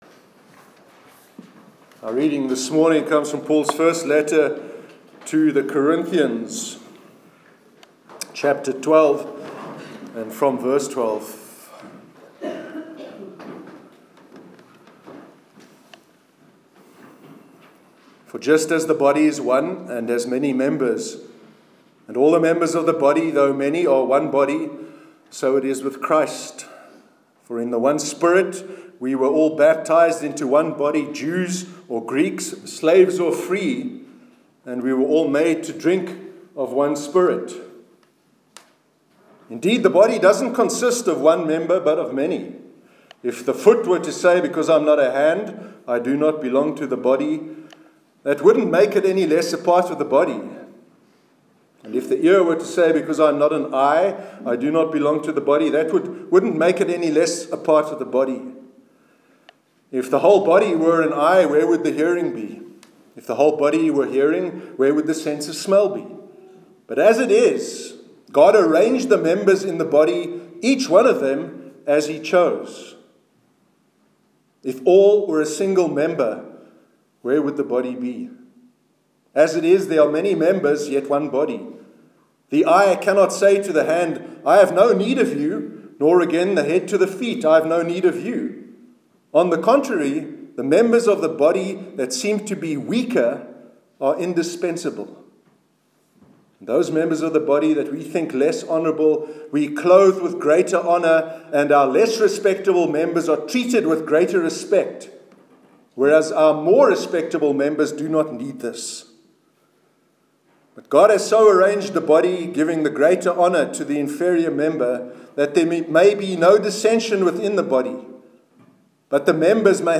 Sermon on the work of the Church- 19 March 2017